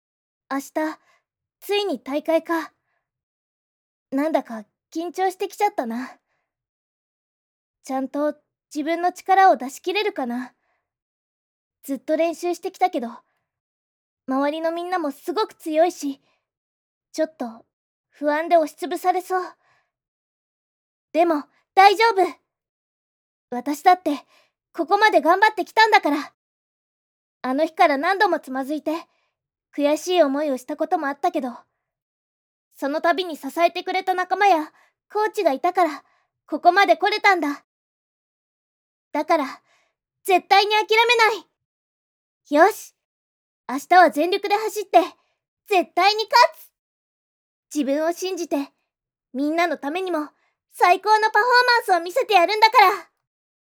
次にアニメ声の出し方5選を意識して読んでみました。
【アニメ声の出し方5選を意識】